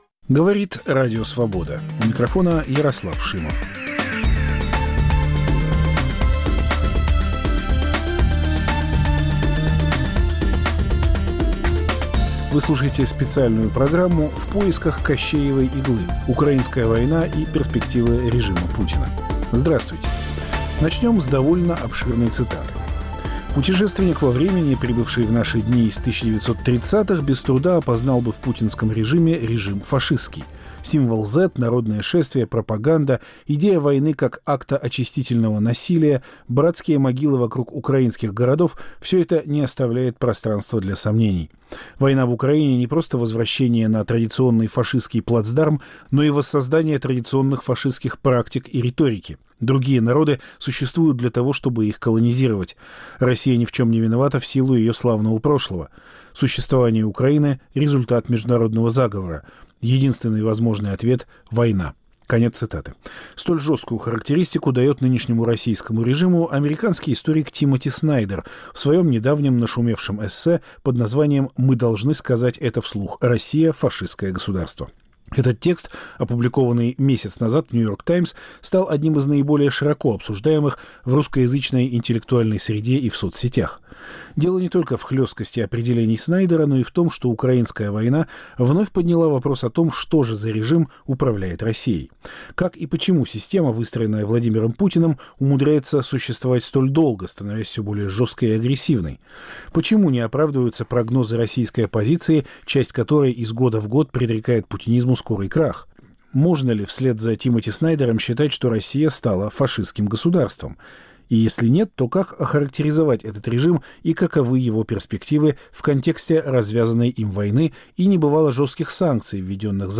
Как и почему система, выстроенная Владимиром Путиным, умудряется существовать столь долго, становясь всё более жесткой и агрессивной? Собеседник Радио Свобода